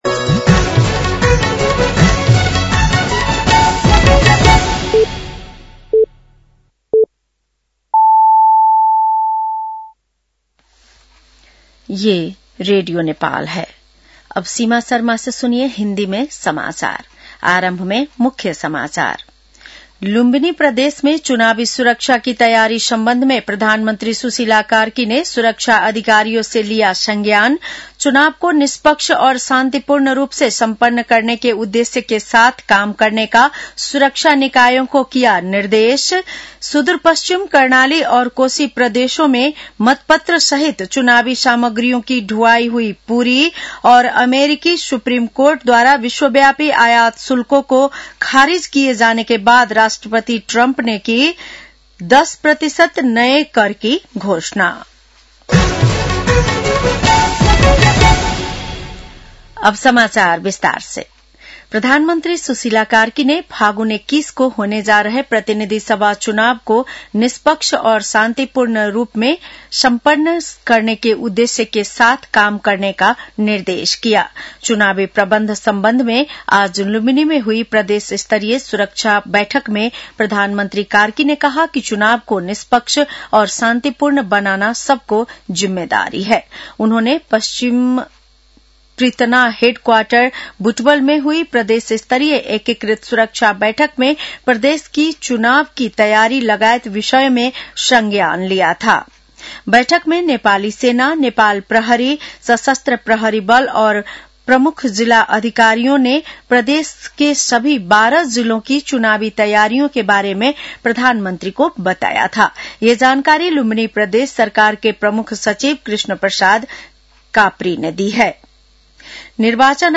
बेलुकी १० बजेको हिन्दी समाचार : ९ फागुन , २०८२